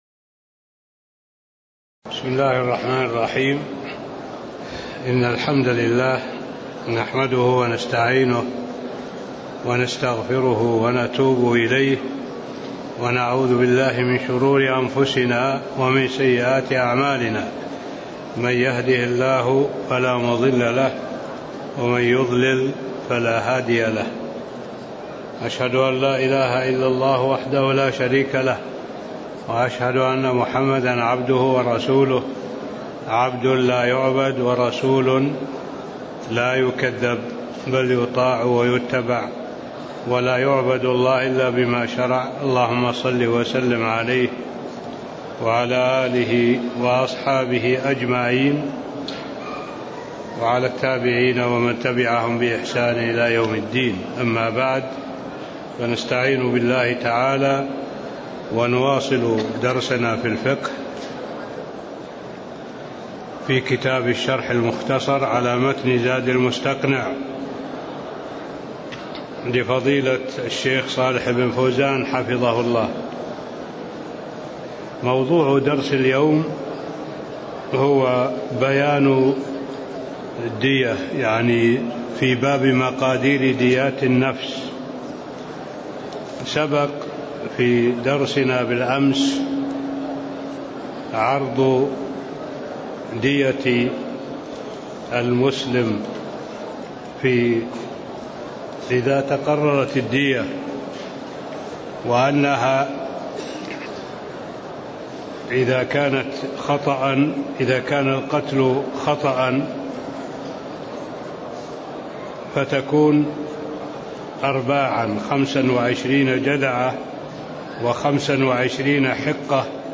تاريخ النشر ١٥ شوال ١٤٣٥ هـ المكان: المسجد النبوي الشيخ: معالي الشيخ الدكتور صالح بن عبد الله العبود معالي الشيخ الدكتور صالح بن عبد الله العبود باب مقادير ديات النفس (04)